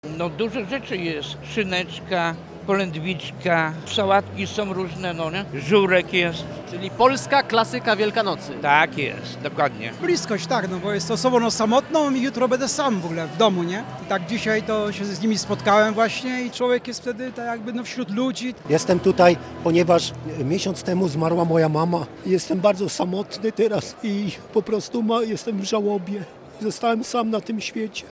Spotkanie organizowane przez Caritas Archidiecezji Gdańskiej odbyło się w Hali Stulecia.
Posłuchaj, co mówili uczestnicy spotkania: